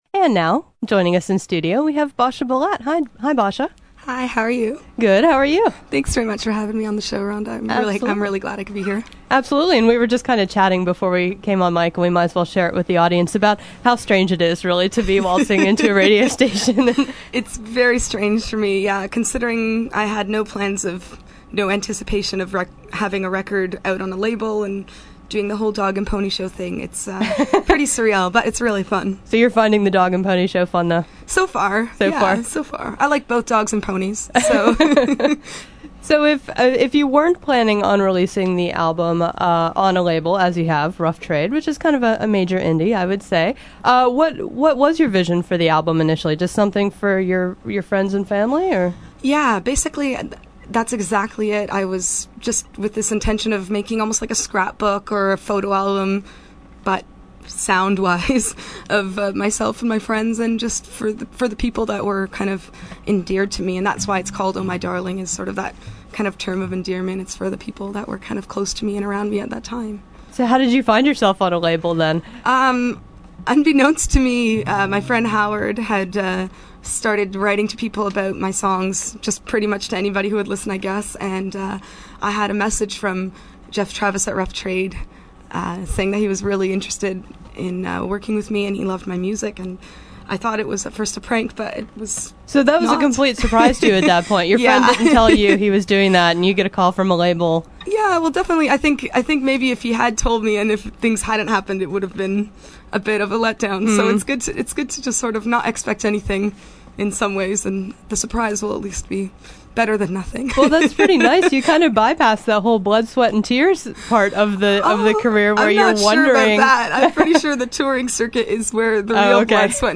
interview
played a couple of songs